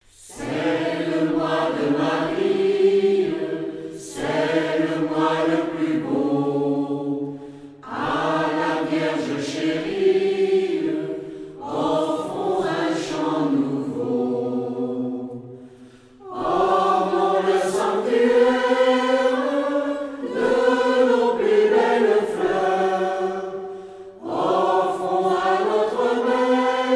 circonstance : cantique
Groupe vocal
Pièce musicale éditée